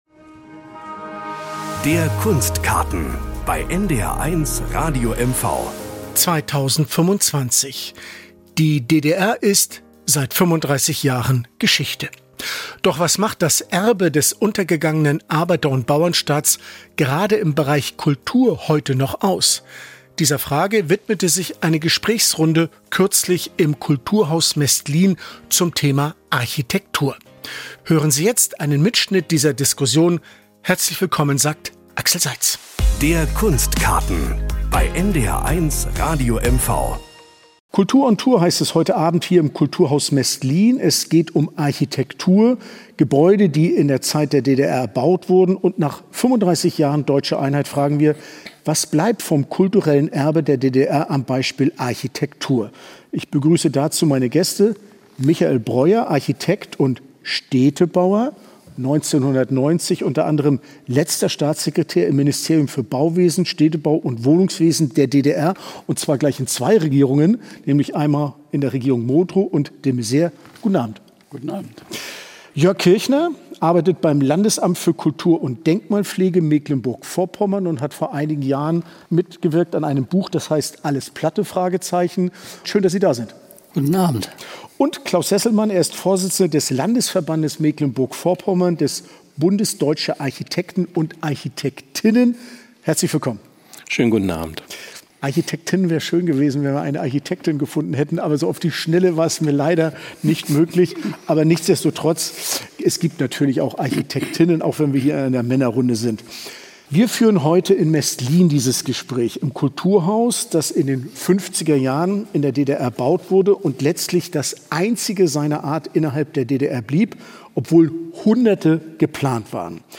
Im Kulturhaus Mestlin